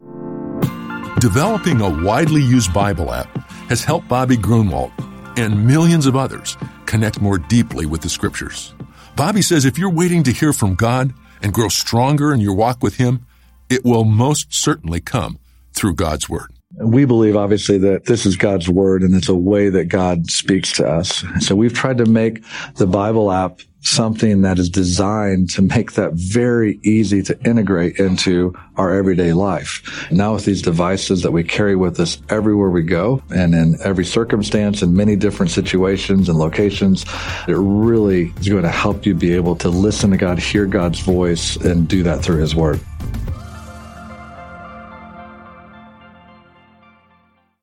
Each day, you’ll hear a short audio message with simple ideas to help you grow in your faith.